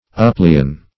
Uplean \Up*lean"\, v. i. To lean or incline upon anything.